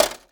GLASS_Hit_01_mono.wav